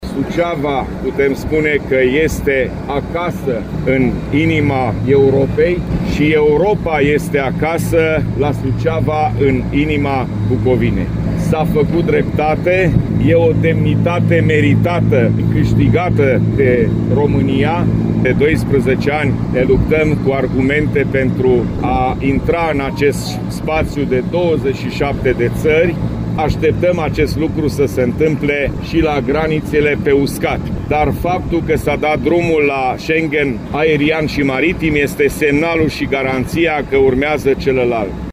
Prezent pe aeroport, președintele Consiliului Județean Suceava GHEORGHE FLUTUR a declarat că această aderare este “un mare câștig pentru cetățenii români”.